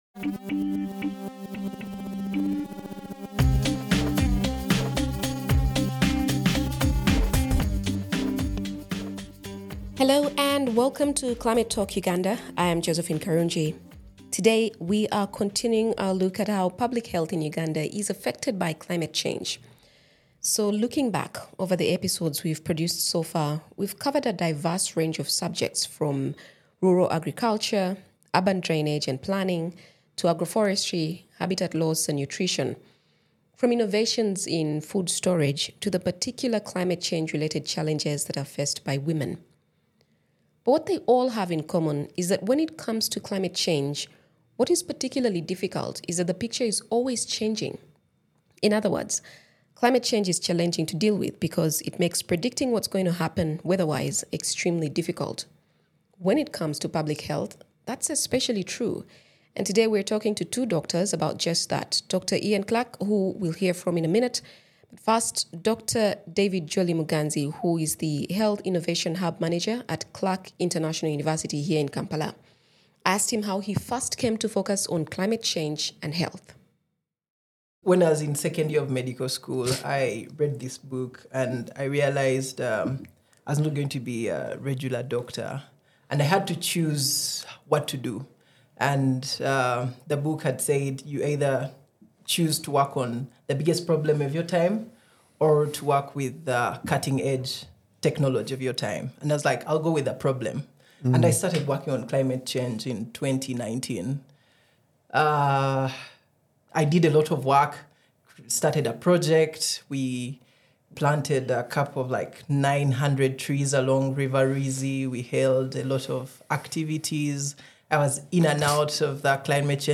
In this wide-ranging conversation, we touch on many aspects of the healthcare landscape in Uganda, and the idea that climate change can be a driving force for innovation.